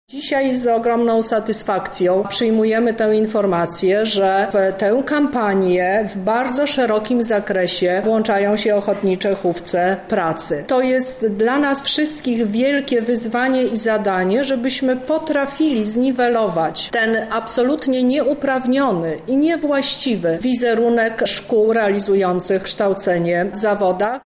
– mówi Teresa Misiuk, kurator oświaty w Lublinie.